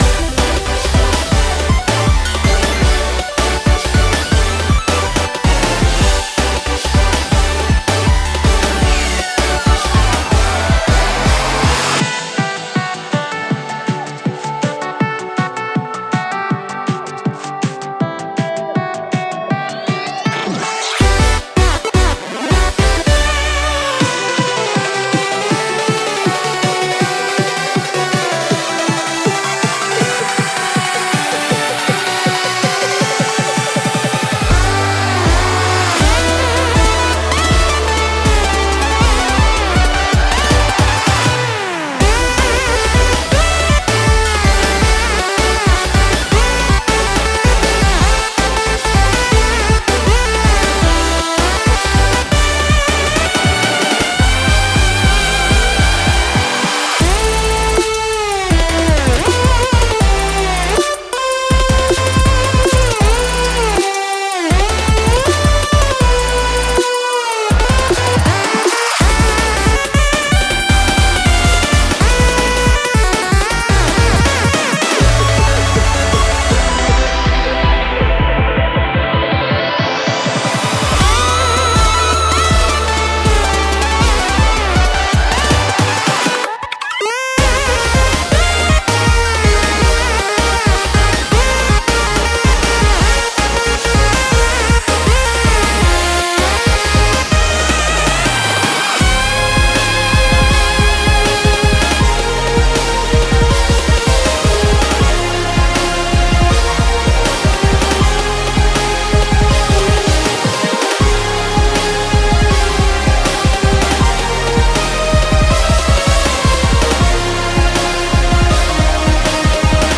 BPM80-160
Audio QualityPerfect (High Quality)
Comments[UPLIFTING CHIPTUNE]